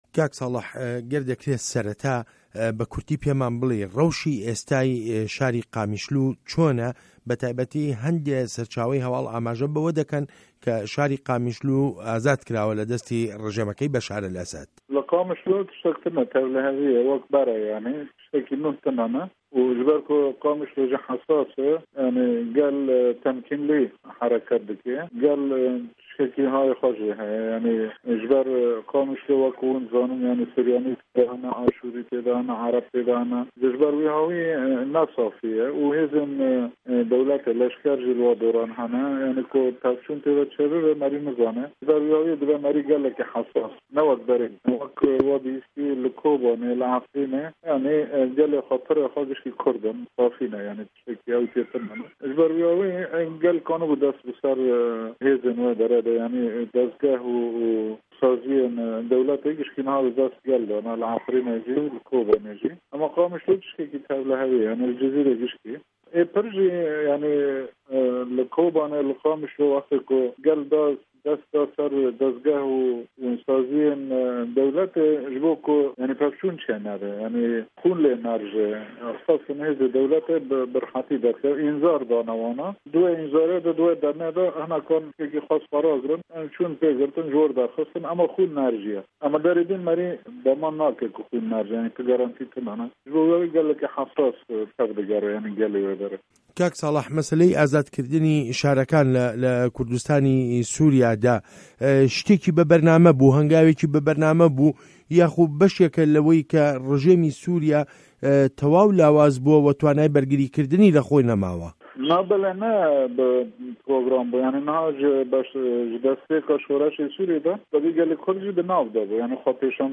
وتووێژ له‌گه‌ڵ ساڵح موسلم